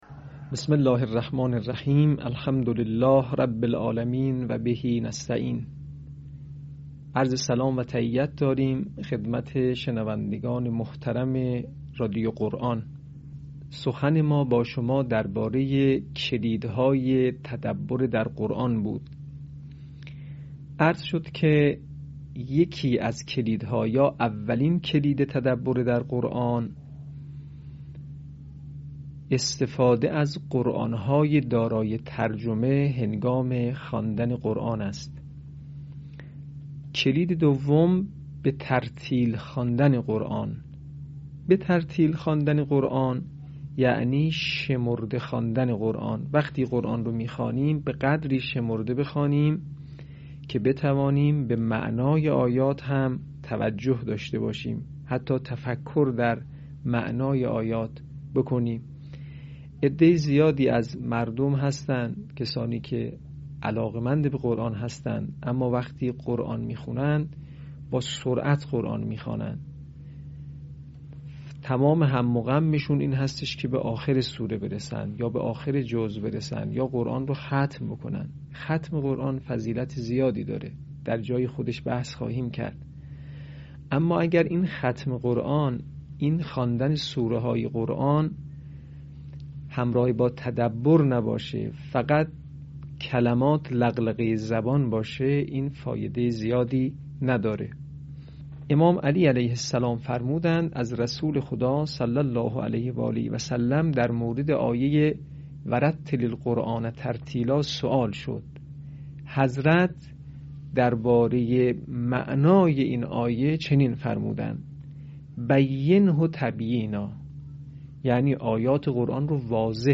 به همین منظور مجموعه آموزشی شنیداری (صوتی) قرآنی را گردآوری و برای علاقه‌مندان بازنشر می‌کند.
آموزش قرآن